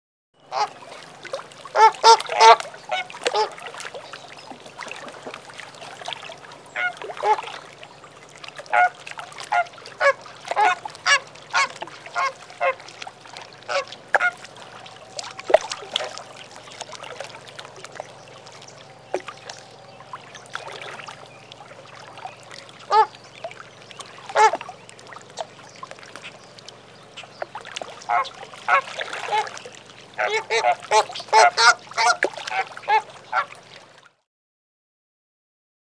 Le flamant-rose | Université populaire de la biosphère
il cacarde, cagnarde, criaille, glousse, siffle
flamant.mp3